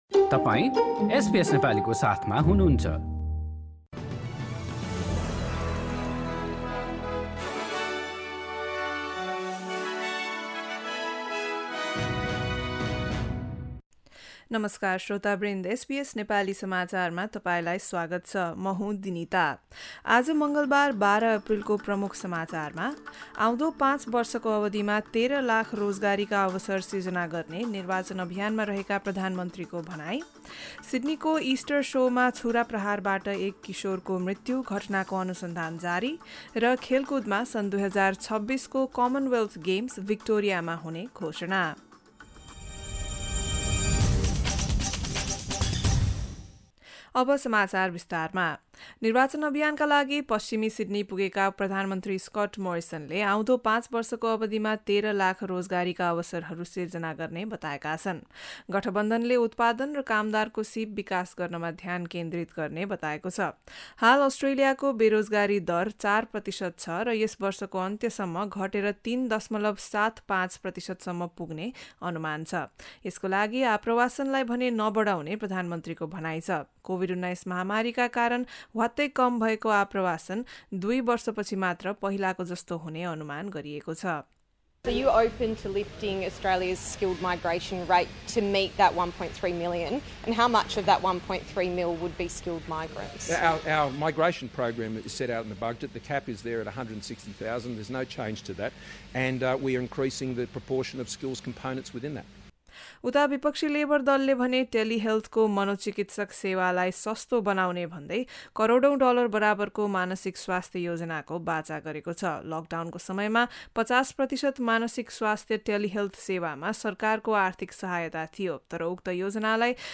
एसबीएस नेपाली अस्ट्रेलिया समाचार: मङ्गलबार १२ अप्रिल २०२२